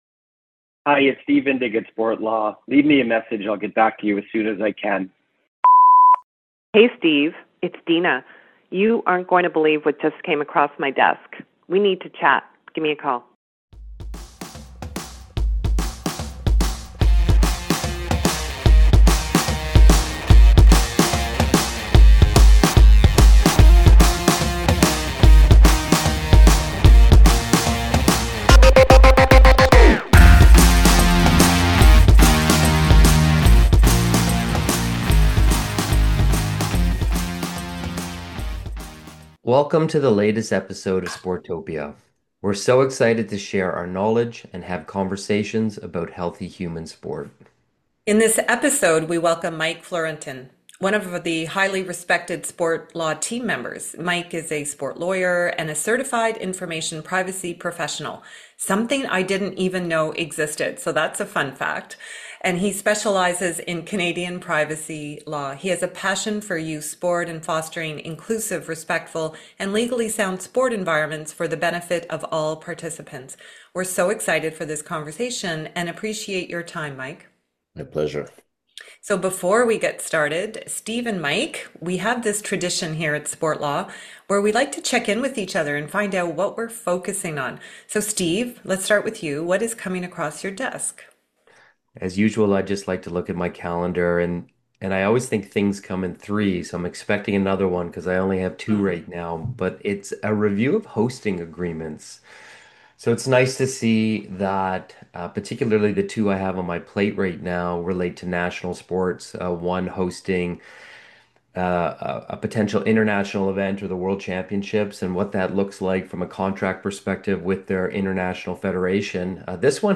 Listen in to the wide-ranging conversation regarding the importance of effective policies, business planning and social justice.